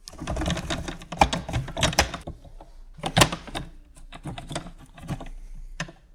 Drawer Stuck Sound
household